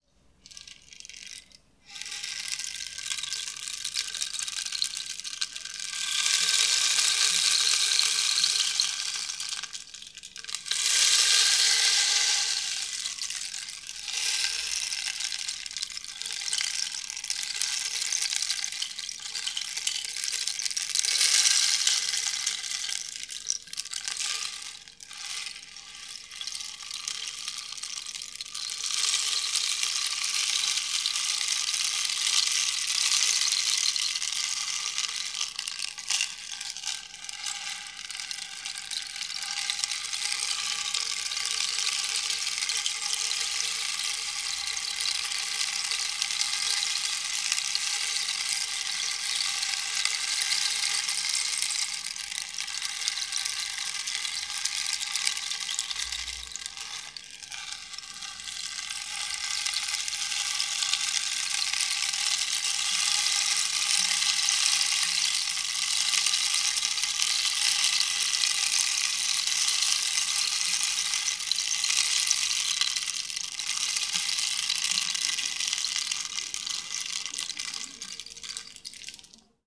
You may extend this activity by using a portable audio player such as a Bluetooth speaker paired with Smartphone for playback of the audio samples offered: Rain Stick and Throat Singing.